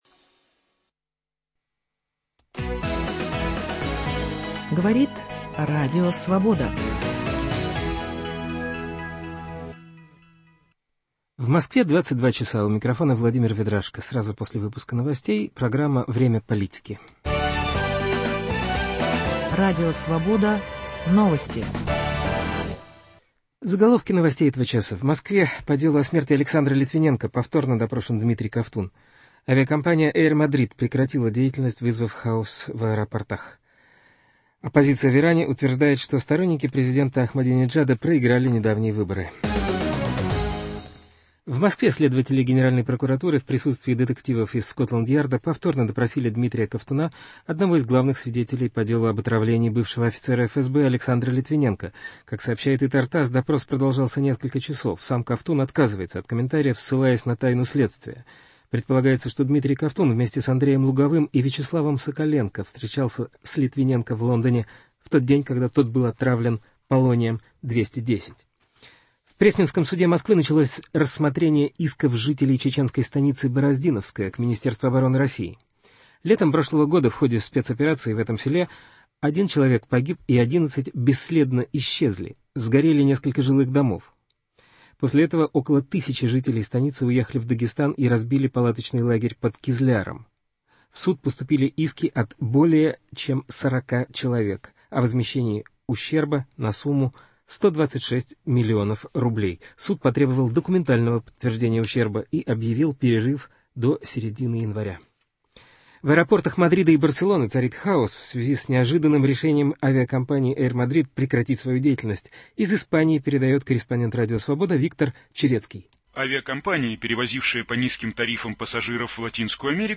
В прямом эфире события последней недели обсуждают: лидер Партии возрождения России, депутат Государственной Думы Геннадий Селезнев и первый заместитель председателя партии Союз правых сил Леонид Гозман.